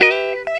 GTR 51 EM.wav